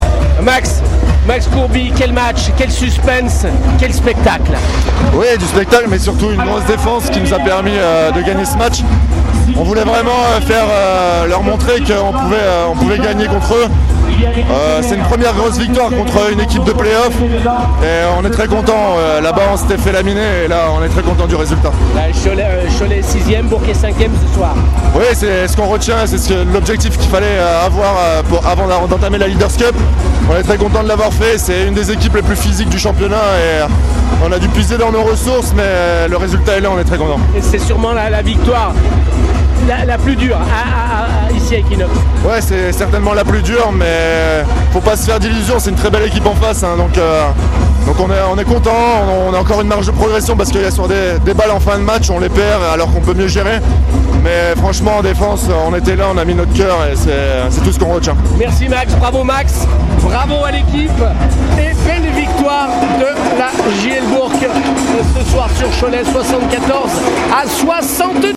Retrouvez les réactions après le beau duel entre la JL et Cholet.
LES RÉACTIONS